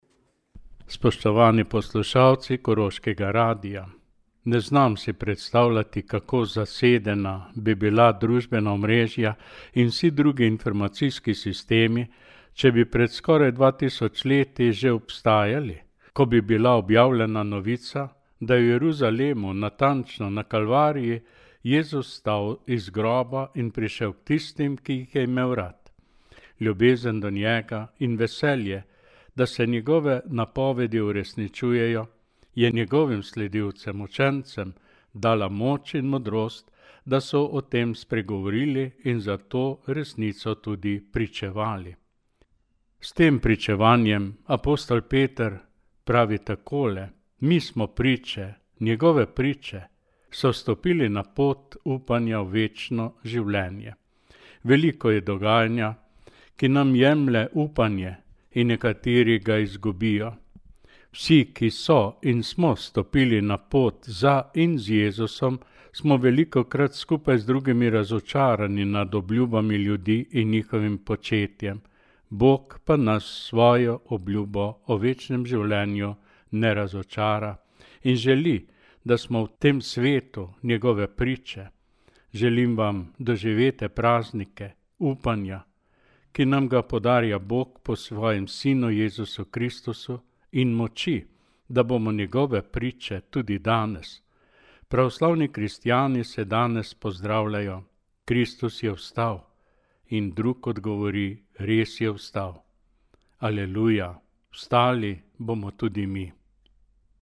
Poslanico